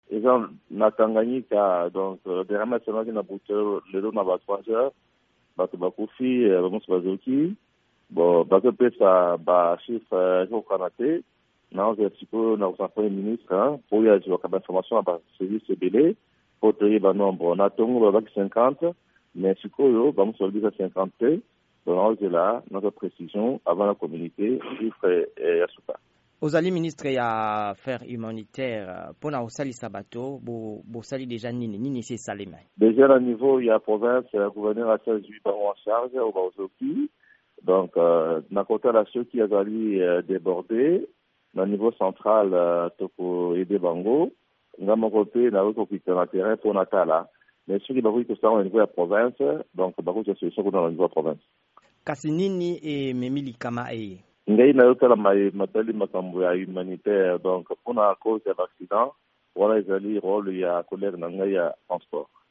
Tolanda M. Mbikayi.